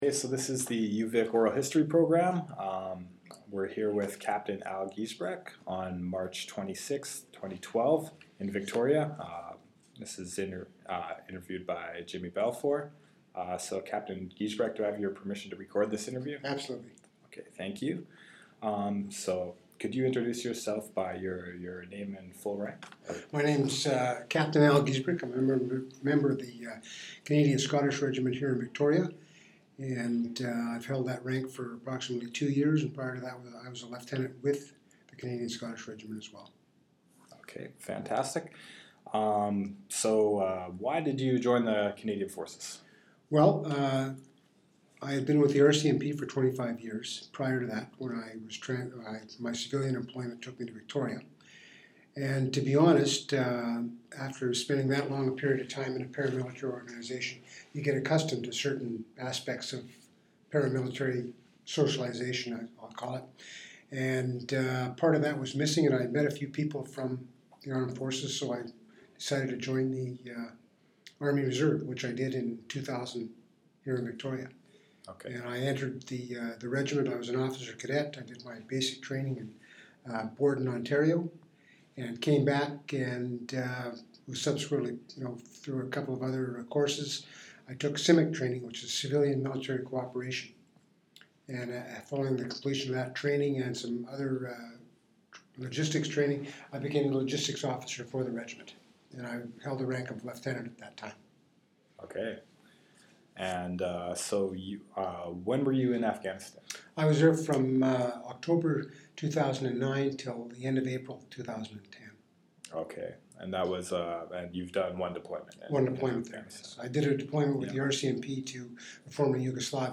Interview took place on March 26, 2012.